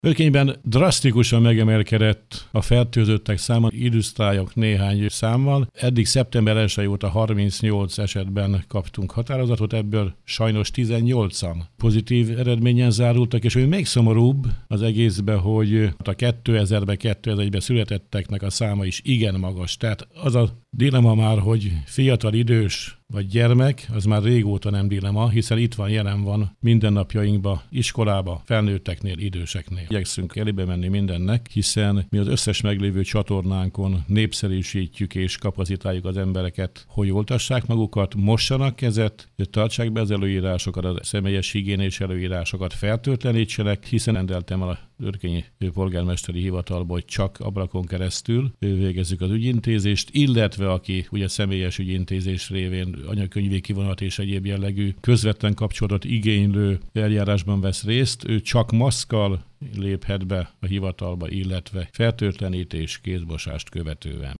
Kulcsár István polgármestert hallják.